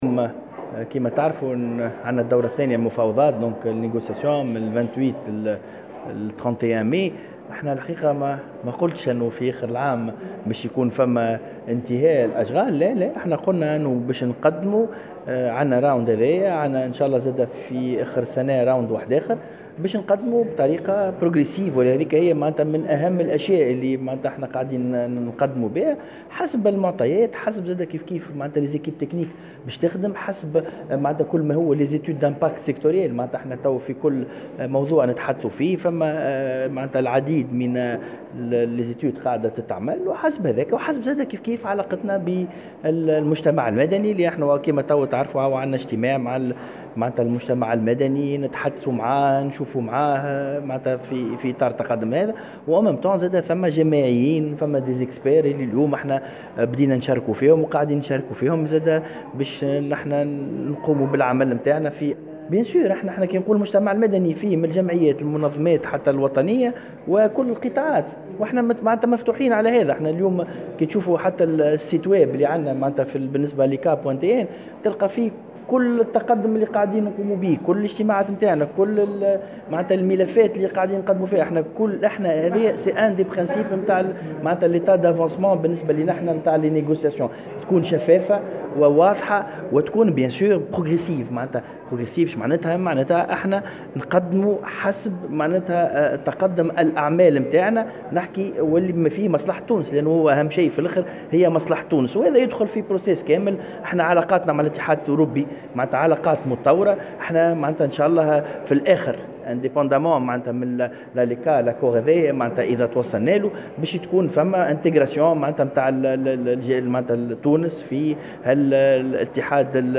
وأفاد كاتب الدولة للتجارة الخارجية والمفاوض الرئيس لاتفاق "الأليكا" هشام بن أحمد في تصريح لمراسلة الجوهرة "اف ام"أن قواعد التفاوض تفرض الأخذ بعين الإعتبار الفرق في مستوى التنمية بين تونس والاتحاد الأوروبي من ناحية أخرى مبدأ التدرج في بعض الميادين انطلاقا من مشاركة المجتمع المدني والخبراء والجامعيين وكل الأطراف المعنية.